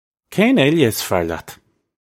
Pronunciation for how to say
Kayn ayluh iss far lyat? (U)
This is an approximate phonetic pronunciation of the phrase.